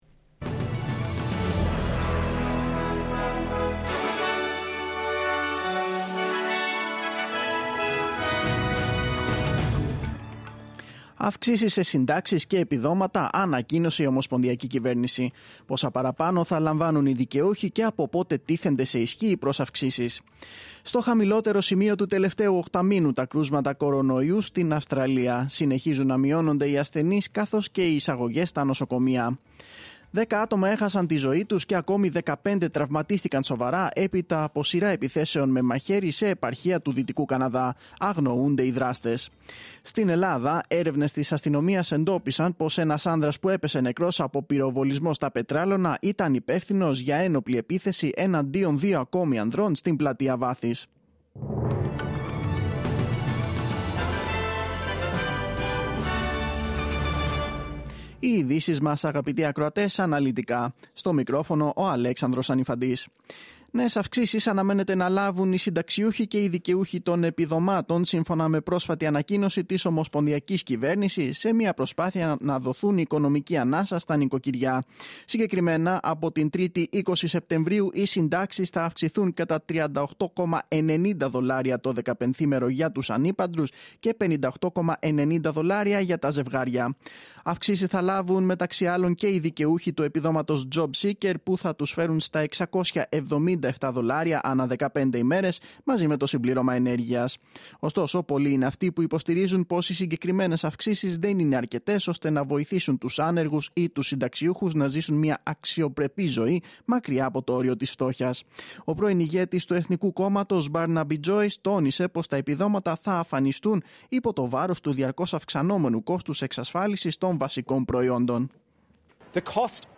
Δελτίο Ειδήσεων: Δευτέρα 5-9-2022